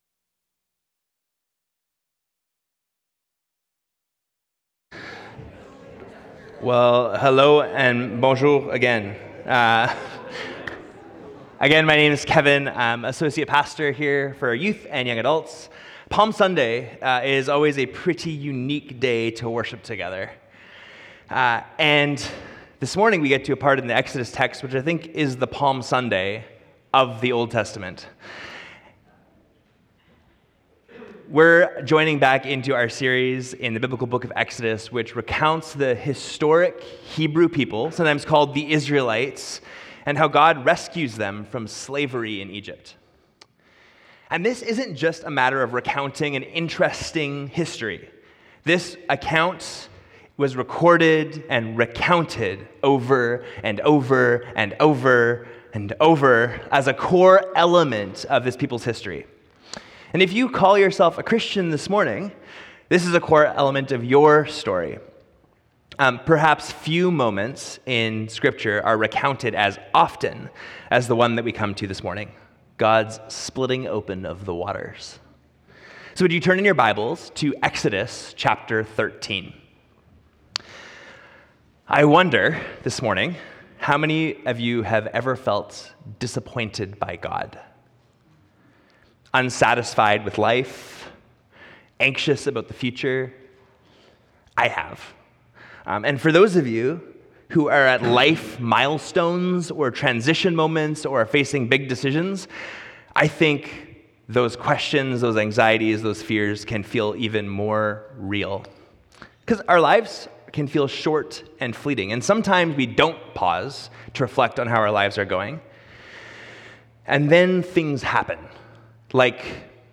Sermon Series – Hillside Church